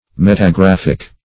Metagraphic \Met`a*graph"ic\, a.